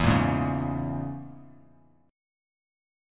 E6b5 chord